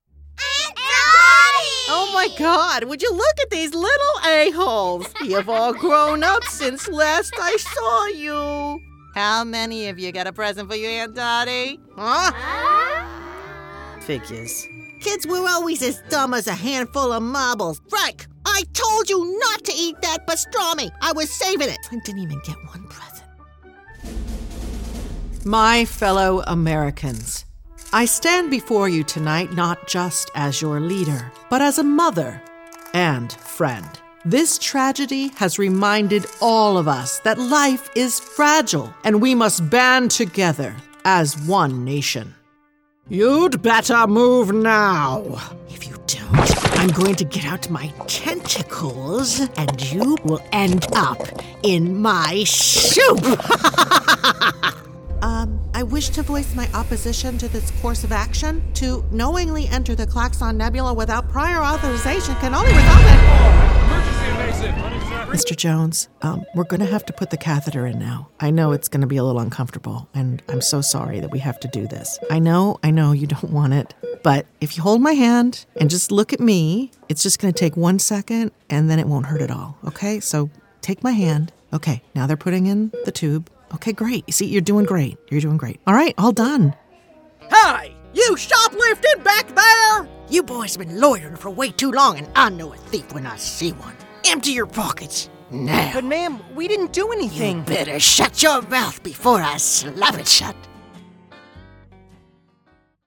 Young Adult, Adult, Mature Adult
Has Own Studio
VOICEOVER GENRE
ANIMATION 🎬